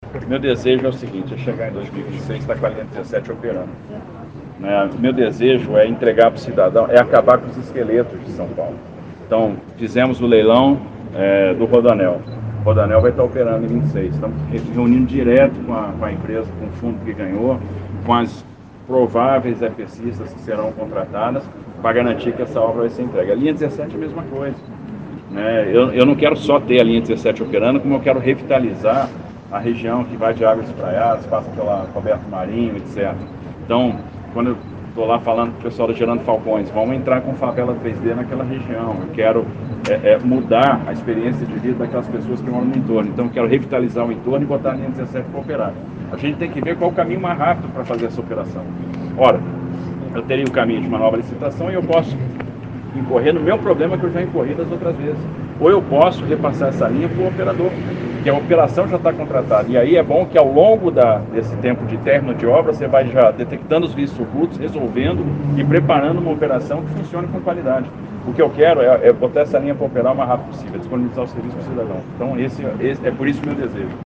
Primeiro a falar do tema, o governador Tarcísio disse que sua intenção é entregar a obra com oito estações, ligando o Aeroporto de Congonhas até a estação Morumbi da linha 9, além de integrar com a linha 5, até o final de seu mandato em 2026.